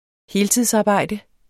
Udtale [ ˈheːltiðs- ]